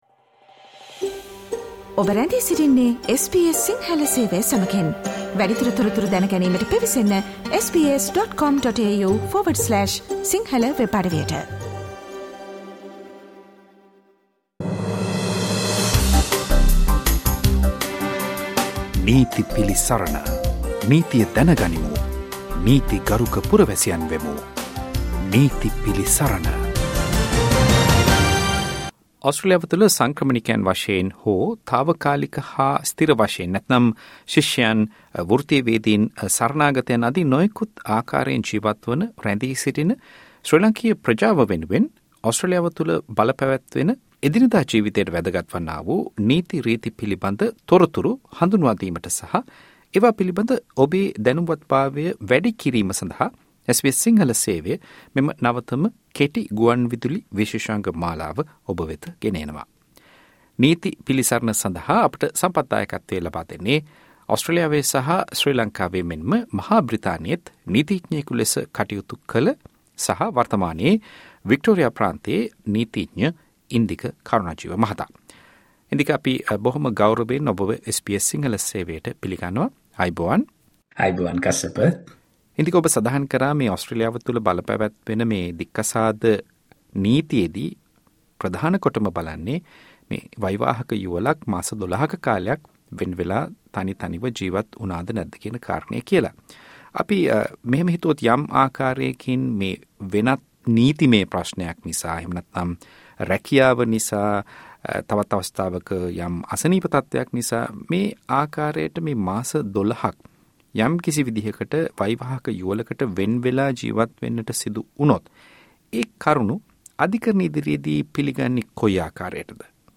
ඕස්ට්‍රේලියාවේ ජීවත් වන ඔබට වැදගත් වන නීති කරුණු පැහැදිලි කරන නීති පිළිසරණ විශේෂාංගය. මෙම වැඩසටහන දික්කසාද නීතිය පිළිබඳ සාකච්ඡාවේ දෙවැනි කොටස.